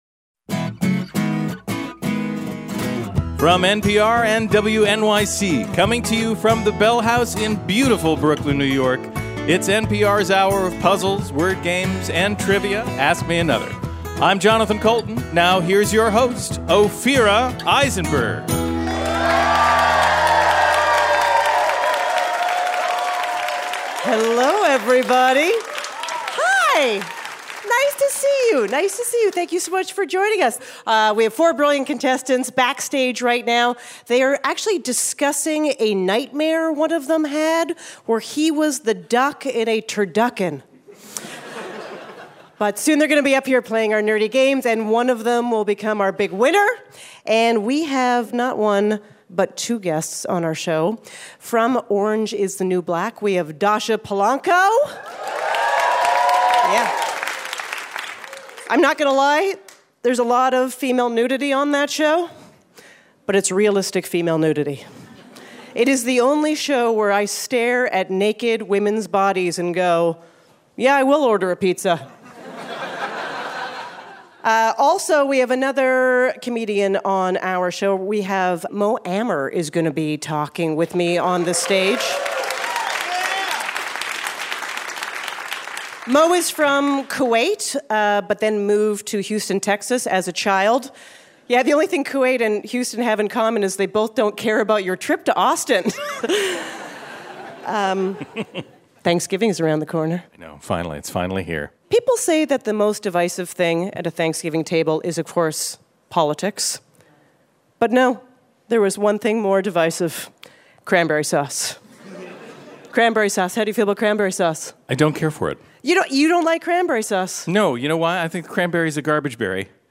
Advertisement Dascha Polanco And Mo Amer: Orange Is The New Black Flag Play 51:38 Download Audio November 13, 2018 facebook Email Ophira Eisenberg chats with Dascha Polanco on Ask Me Another at the Bell House in Brooklyn, New York.